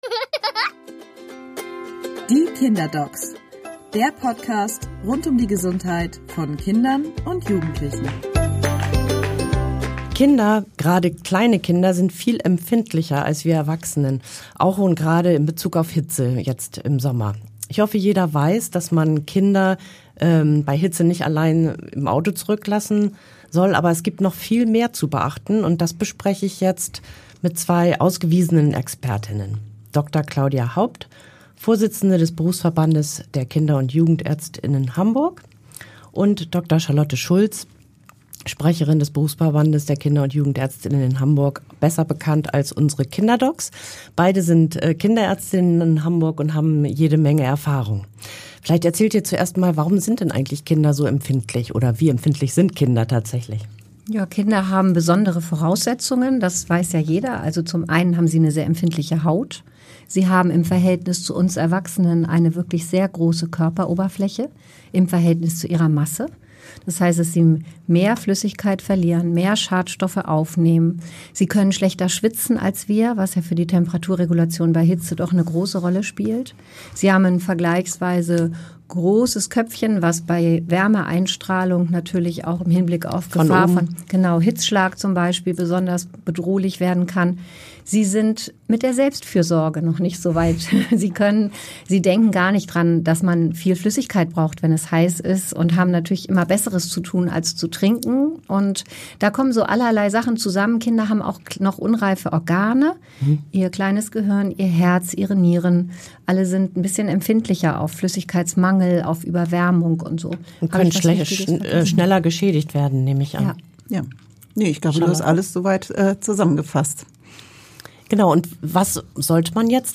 Zwei Hamburger Kinderärztinnen erklären, warum gerade kleine Kinder für Hitze besonders anfällig sind und was Eltern beachten müssen.